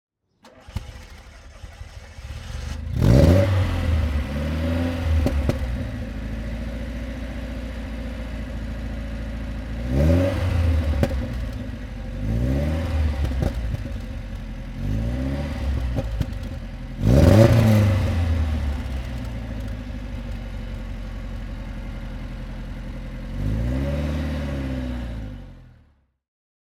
Motorsounds und Tonaufnahmen zu Alfa Romeo Fahrzeugen (zufällige Auswahl)
Alfa Romeo Spider 2.0 (1984) - Starten und Leerlauf